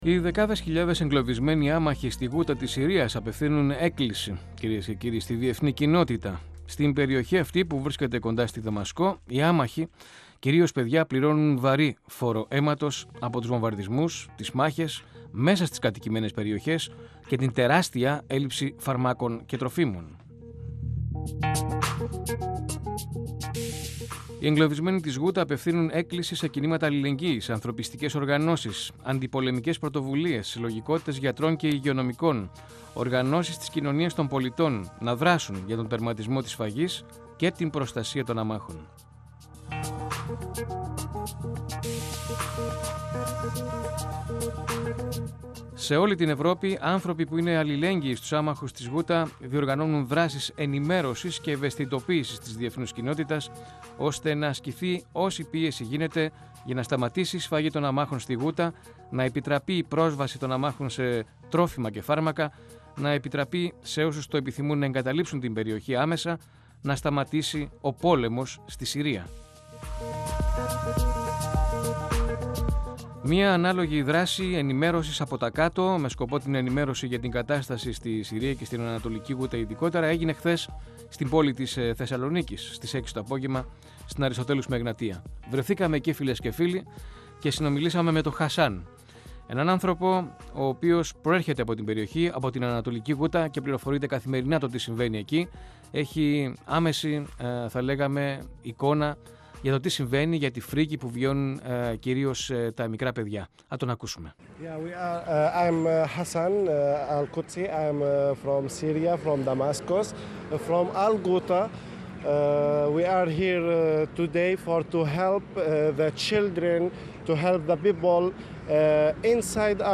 Πάνω τους αφέθηκαν συμβολικά, λευκά και κόκκινα λουλούδια. 102FM Βαβελ Συνεντεύξεις ΕΡΤ3